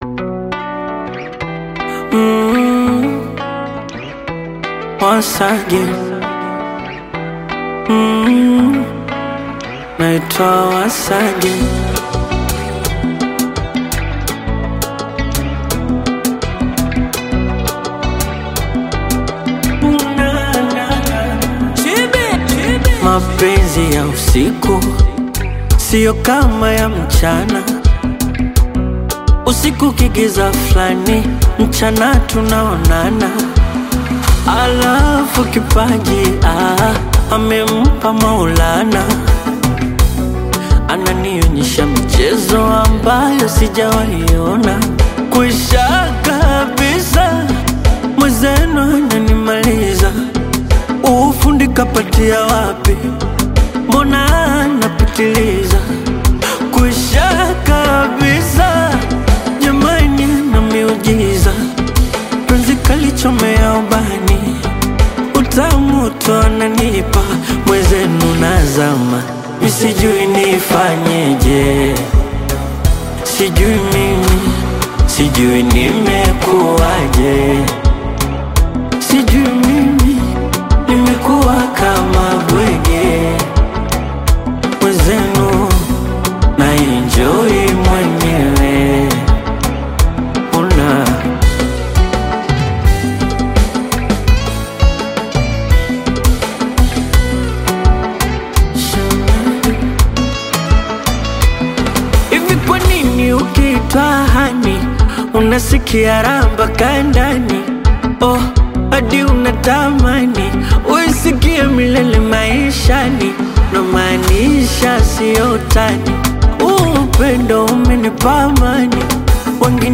romantic Bongo Flava single
Genre: Bongo Flava